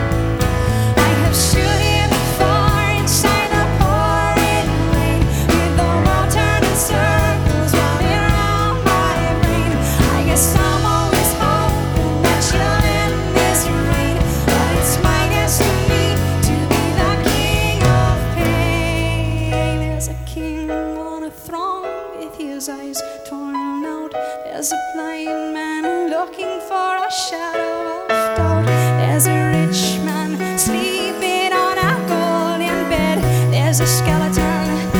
Alternative
Жанр: Альтернатива